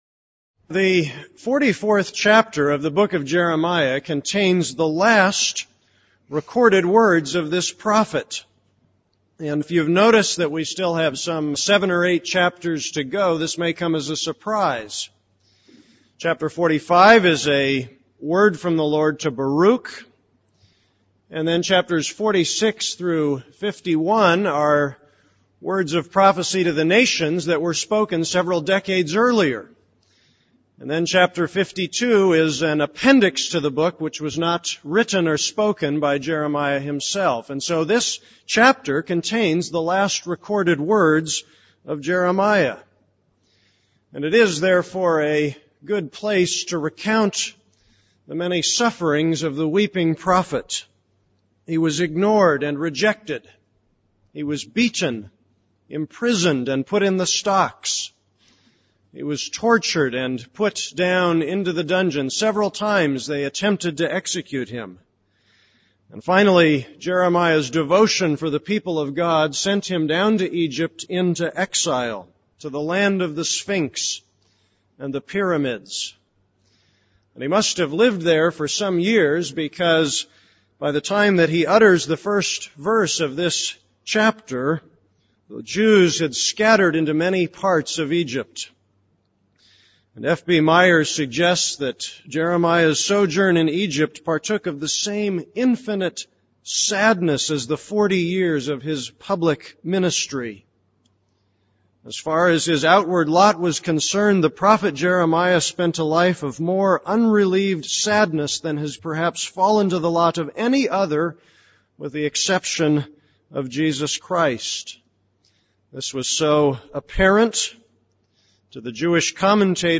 This is a sermon on Jeremiah 44:16-17.